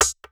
Blocka Perc.wav